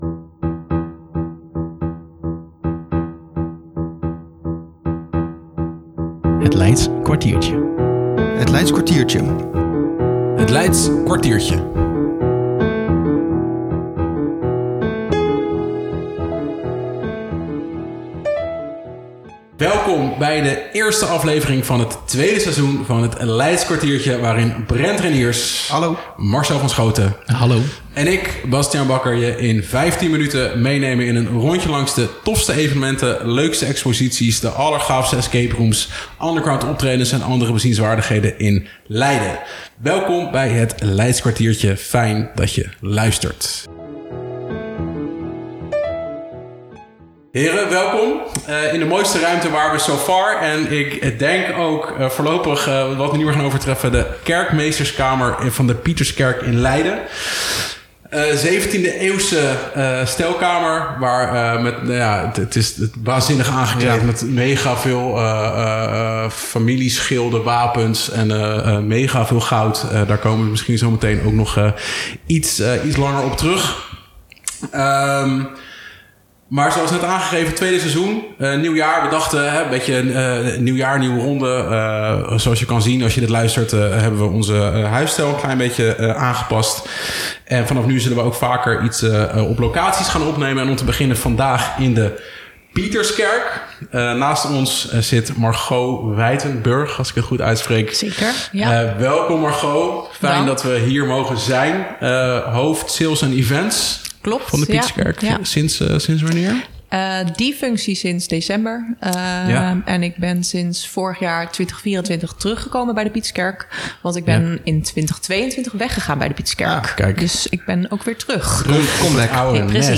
Deze keer nemen we op in de mooiste ruimte van de Pieterskerk; namelijk de Kerkmeesterskamer en spelen we de nieuwe Escape Room van de Pieterskerk: De Generale Repetitie. Ook mogen we een gratis Escape Room weggeven via de Instagram pagina LeidenGram.